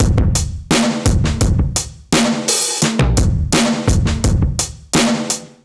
Index of /musicradar/analogue-circuit-samples/85bpm/Drums n Perc
AC_OldDrumB_85-04.wav